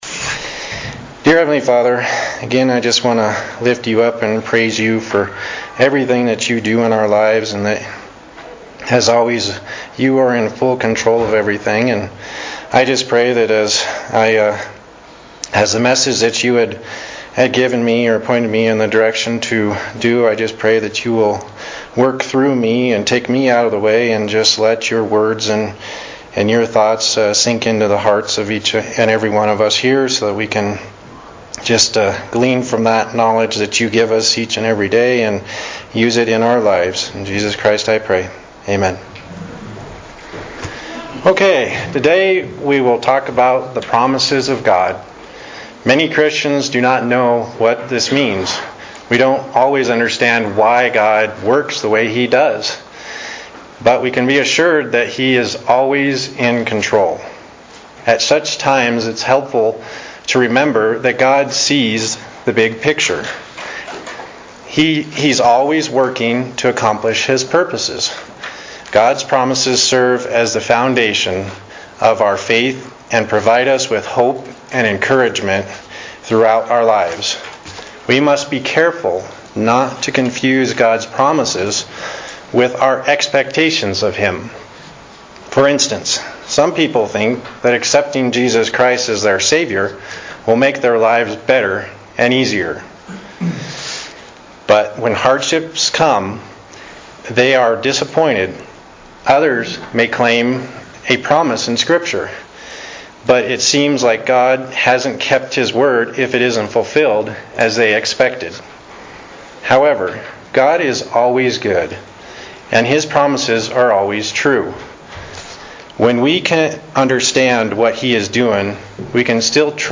Video link below to what was shown in the sermon: